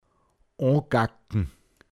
pinzgauer mundart
o(n)gaggn anmachen
se o(n)gaggn sich anmachen;